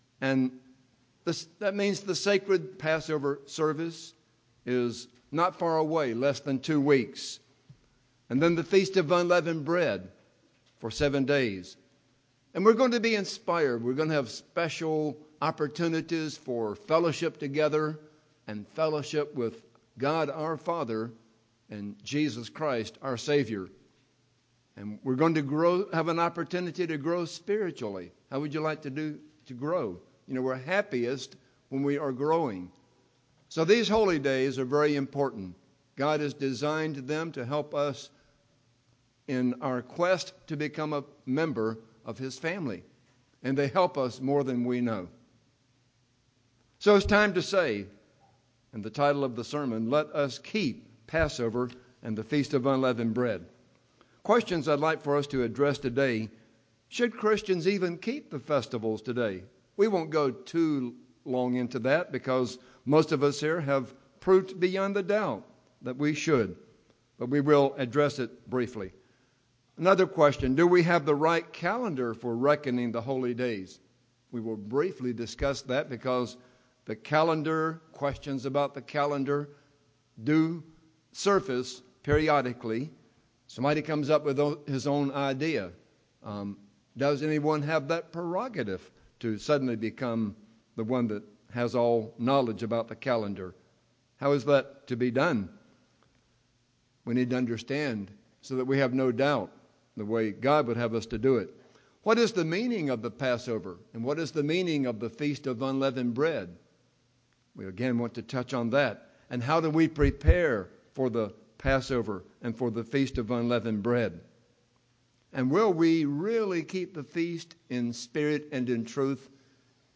God has designed them to help us in our quest to become a member of His family, so let us keep the Passover and Days of Unleavened Bread UCG Sermon Transcript This transcript was generated by AI and may contain errors.